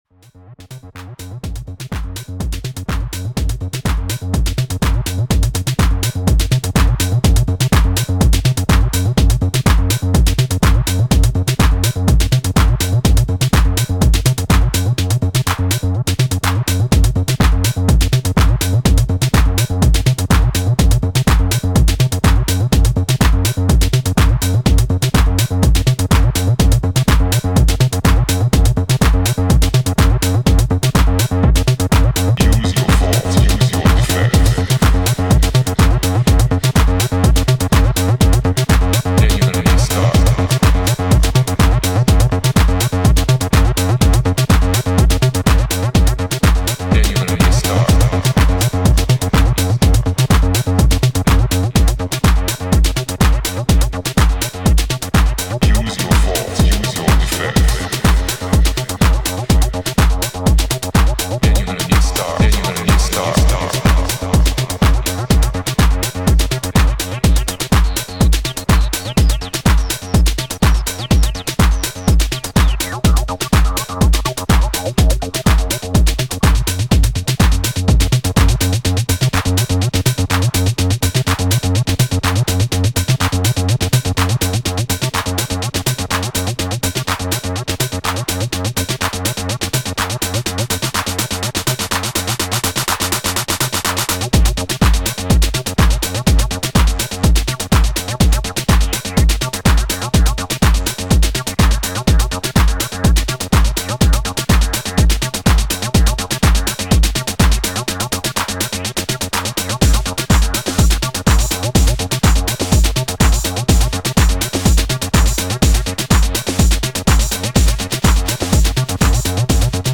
ロウなアシッド・ハウス集となっています